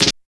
27 SNR BUZZ.wav